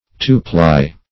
Two-ply \Two"-ply`\, a.